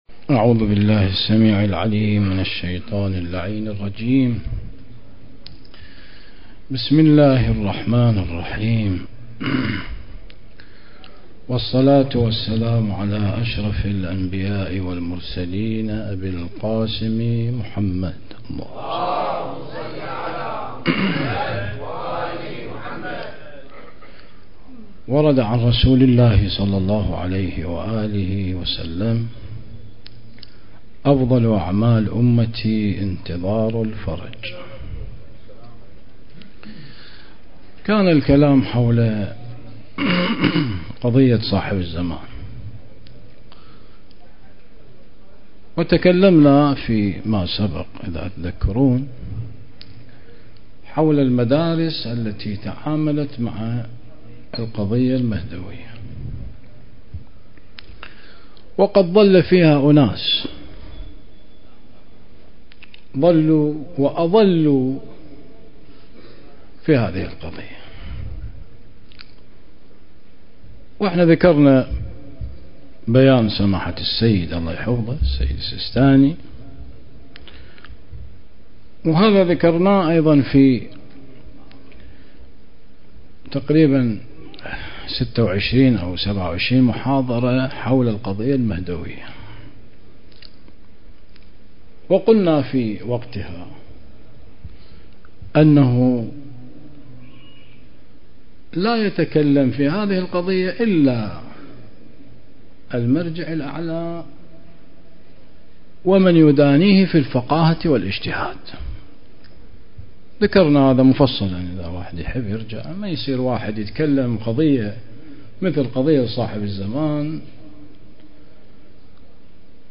المجلس الحسيني ليوم الجمعة ٢٧ شعبان ١٤٤٥هـ